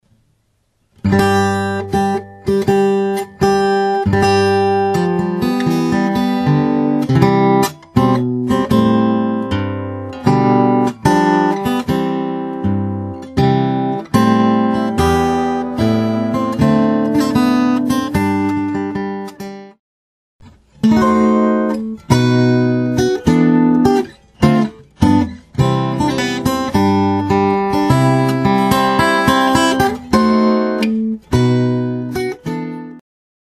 Fingerstyle guitar tab arrangements